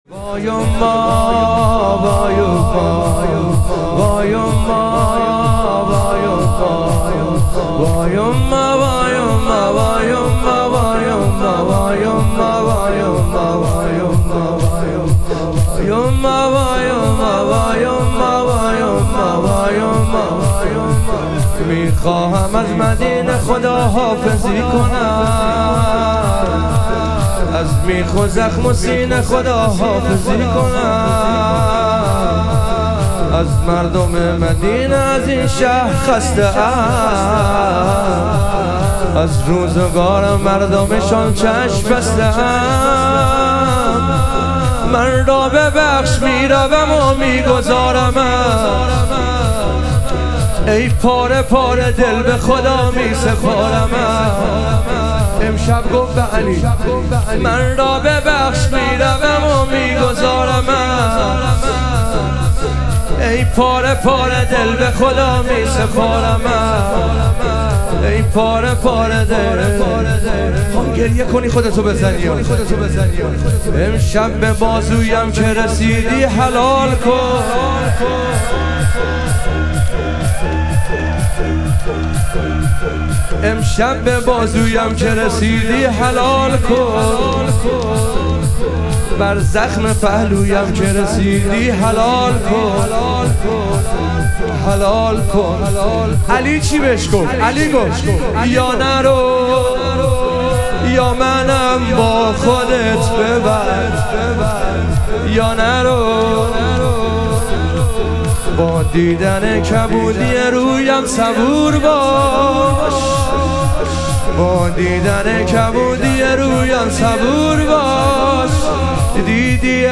ایام فاطمیه دوم - لطمه زنی